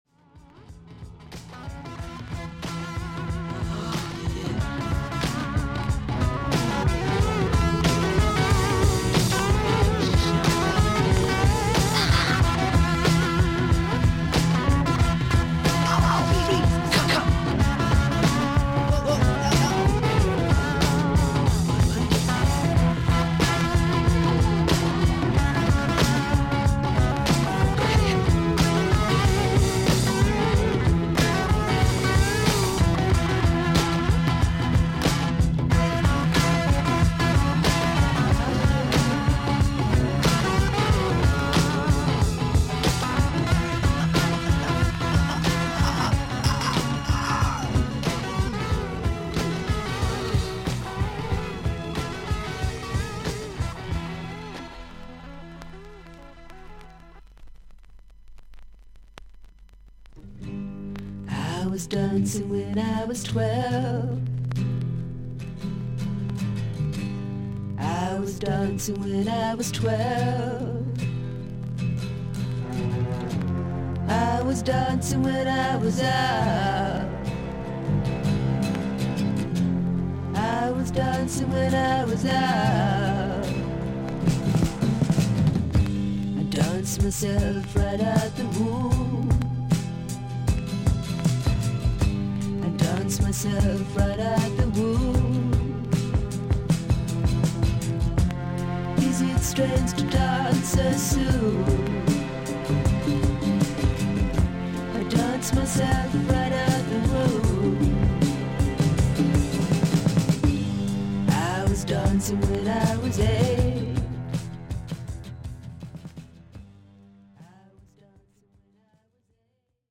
少々軽い周回ノイズがあります。
少々サーフィス・ノイズあり。クリアな音です。